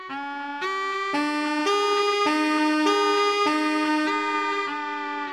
Catégorie Alarme/Reveil